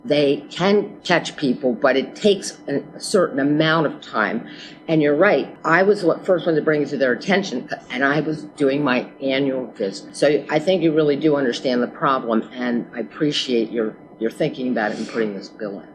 Senate Bill 421 extends the statute of limitations for prosecutions for desecration of these sacred sites. The bill was heard in the Judicial Proceedings Committee where Senator Mary Dulany-James shared her experience with family plots being destroyed.